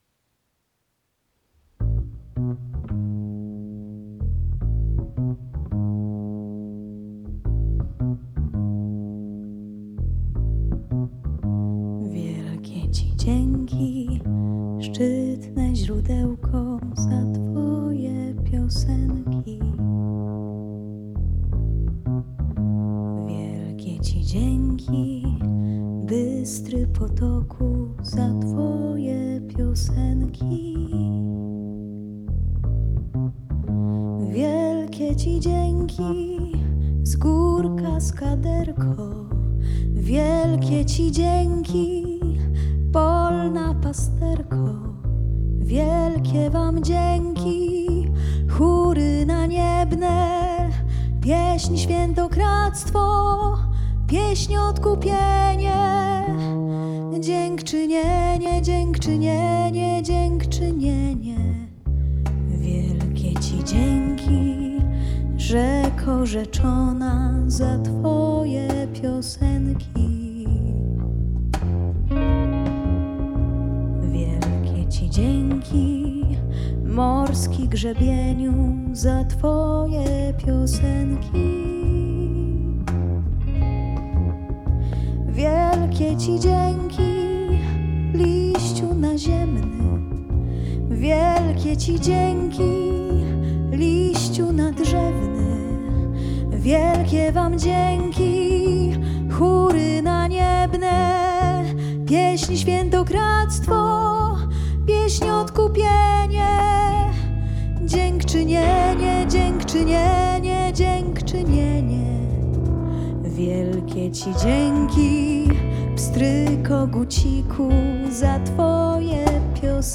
instrumenty klawiszowe
gitary
kontrabas, gitara basowa
perkusja, instrumenty perkusyjne
gitara oud